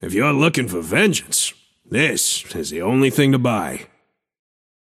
Shopkeeper voice line - If you’re lookin‘ for vengeance, this is the only thing to buy.